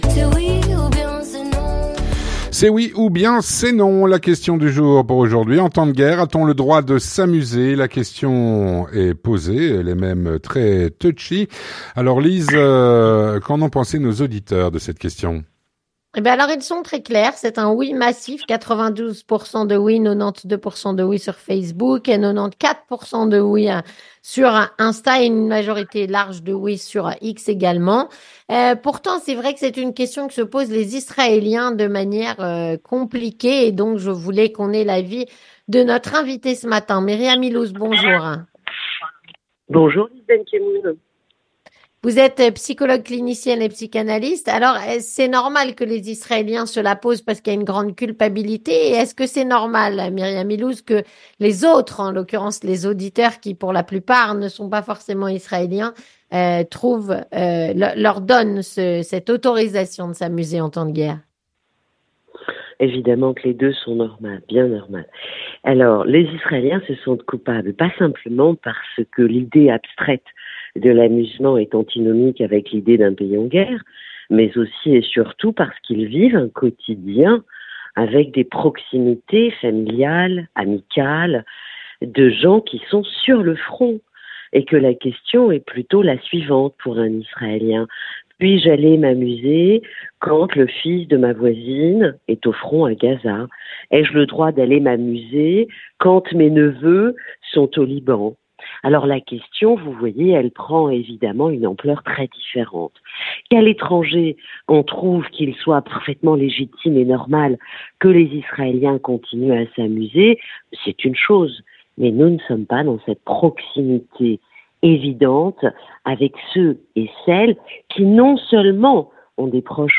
psychologue clinicienne et psychanalyste, répond à "La Question Du Jour".